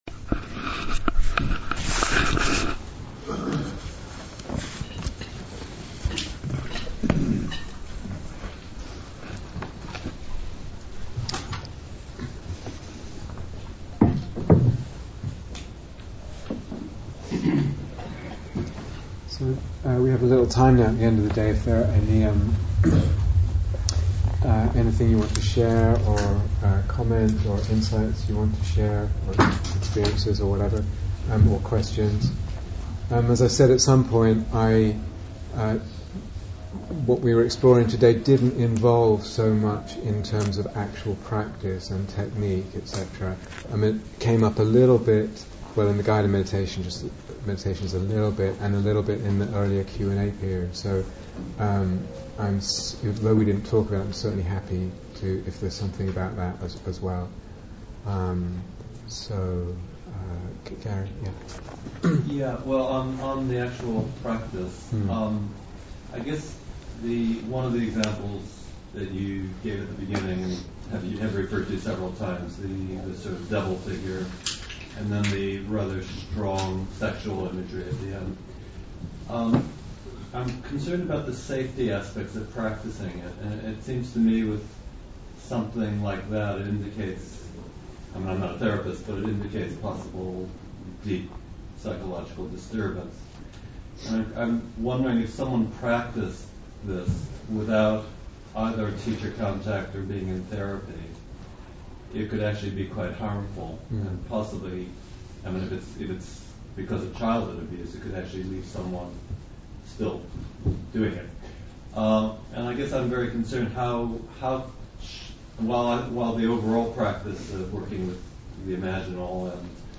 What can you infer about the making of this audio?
Day Retreat, London Insight 2014